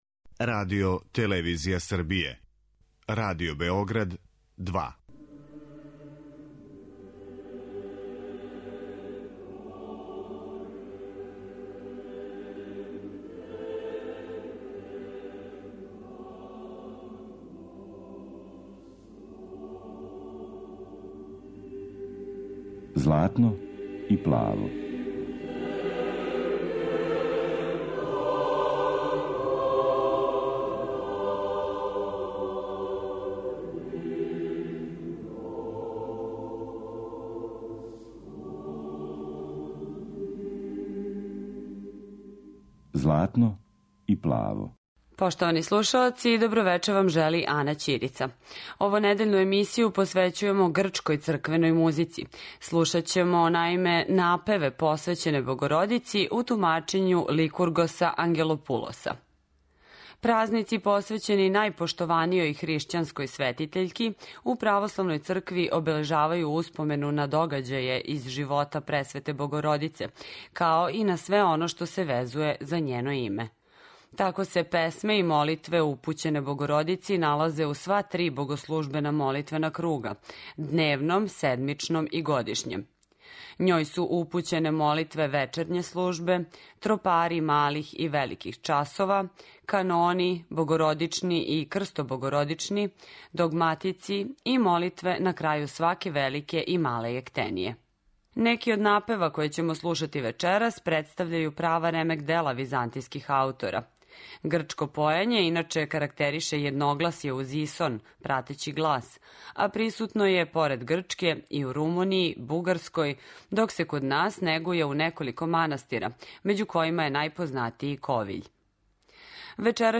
Духовни напеви посвећени Богородици
У овонедељној емисији православне духовне музике, слушаћете грчке напеве посвећене Богородици у извођењу Ликургоса Ангелопулоса и Грчког византијског хора.